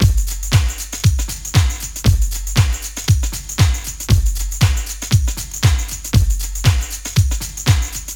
• 118 Bpm 90's Pop Drum Beat G Key.wav
Free breakbeat sample - kick tuned to the G note. Loudest frequency: 1734Hz
118-bpm-90s-pop-drum-beat-g-key-DPe.wav